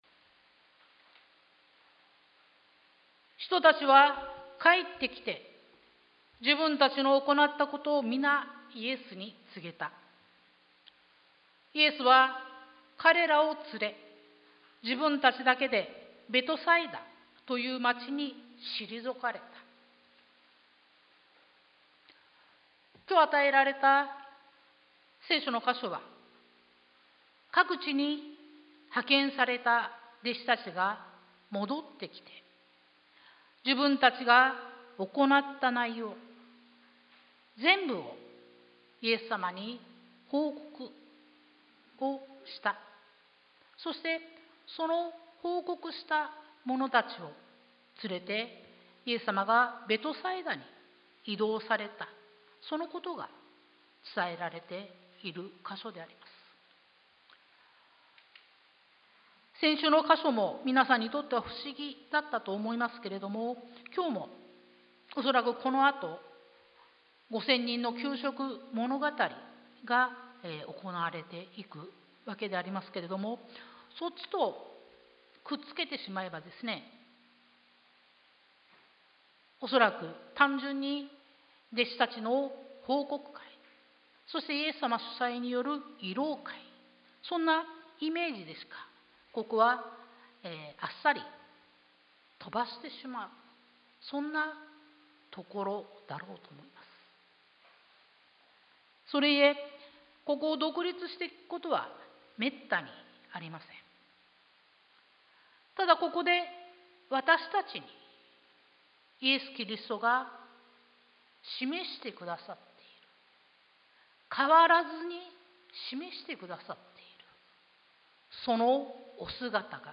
sermon-2023-05-21